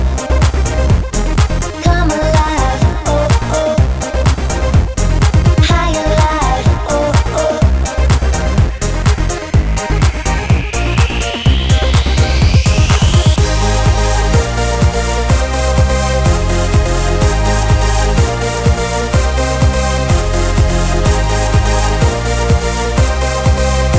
no Backing Vocals R'n'B / Hip Hop 4:07 Buy £1.50